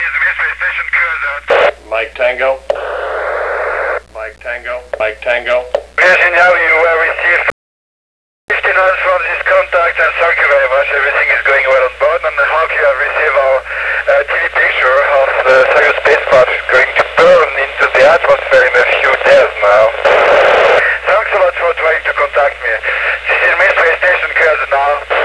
Listen to an actual contact with the Russian MIR Space Station (08/15/99) This was one of the few remaining opportunities to talk to the French Cosmonaut Jean-Peirre Haignere before the station is abandonded in a few weeks.
MIR SPACE STATION - Contact with Fench Cosmonaut Jean-Pierre Haignere
radiomir.wav